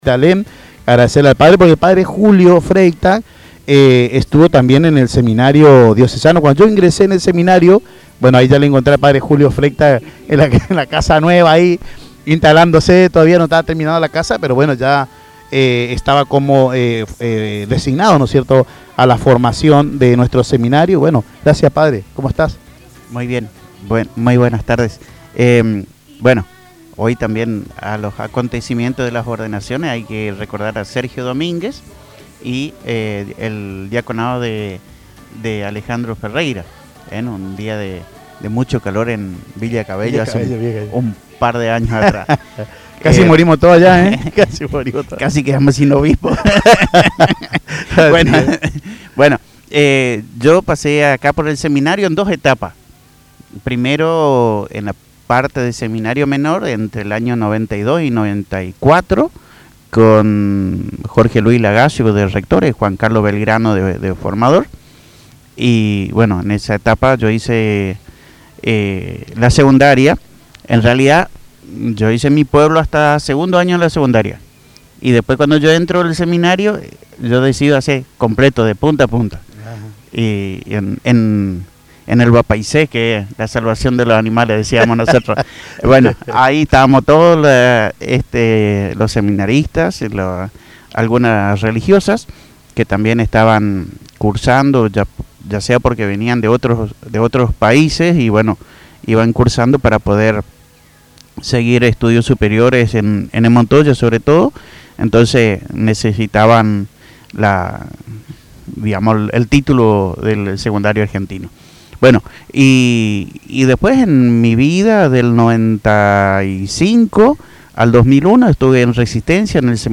El programa, transmitido en horario especial desde el Seminario Diocesano Santo Cura de Ars, tuvo lugar con motivo de la Misa de Acción de Gracias del Seminario, seguida de un momento de fraternidad.